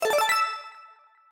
ここではこれを「アイテムを取得した時の効果音」として鳴らしてみましょう。
この効果音は、サンプルゲーム『ニコニコスネーク』の効果音 SE_start.aac, .ogg を改名したものです。
SE_item.aac